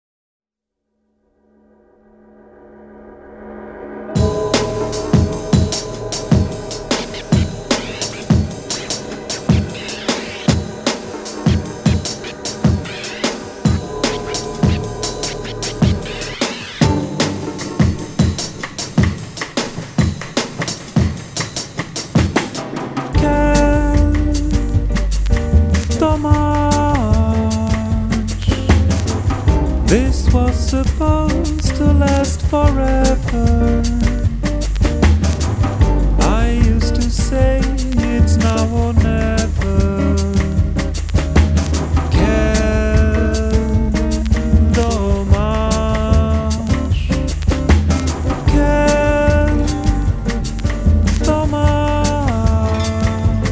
quatre extra-terrestres de la pop électronique
Tout y est très paisible, gentil, heureux.